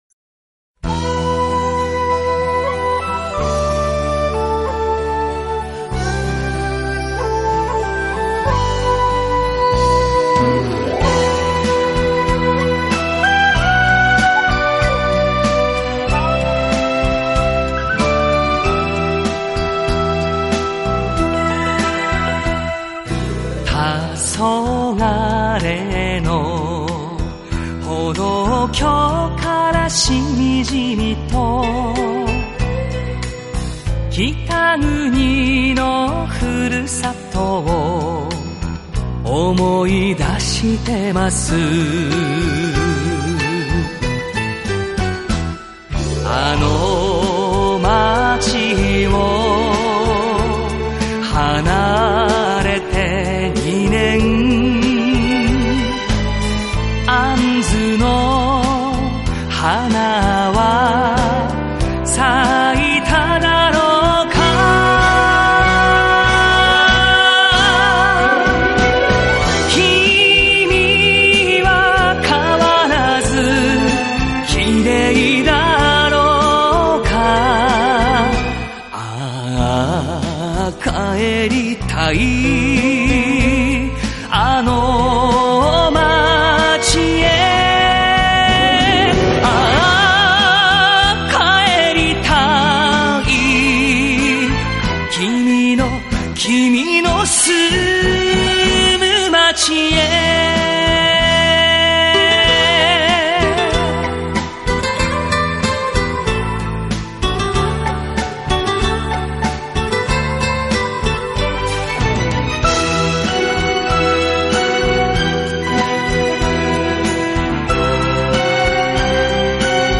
Жанр: enka, folk, jpop